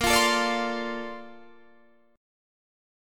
A#M9 Chord
Listen to A#M9 strummed